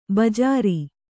♪ bajāra